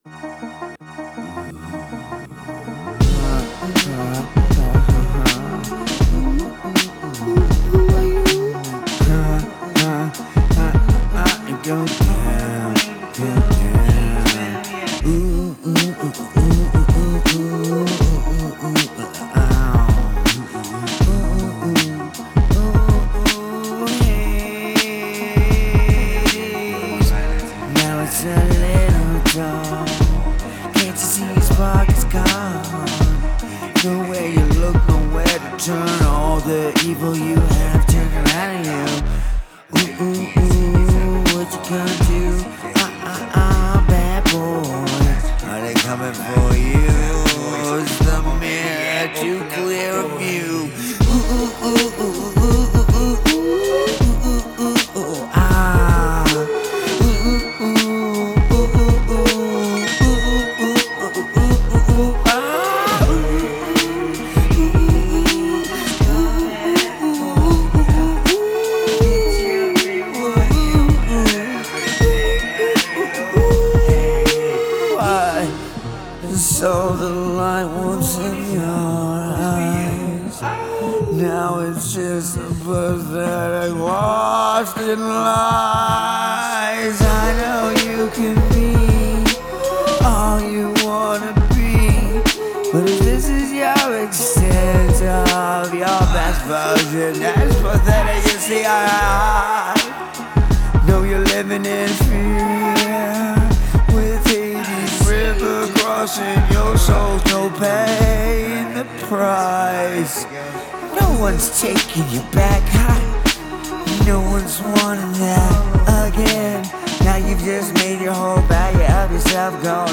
Date: 2026-09-13 · Mood: dark · Tempo: 80 BPM · Key: D major